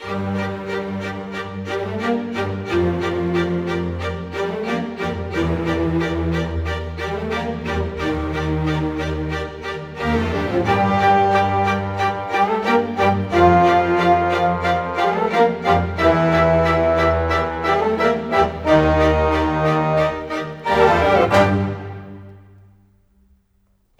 Rock-Pop 07.wav